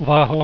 wahoo.wav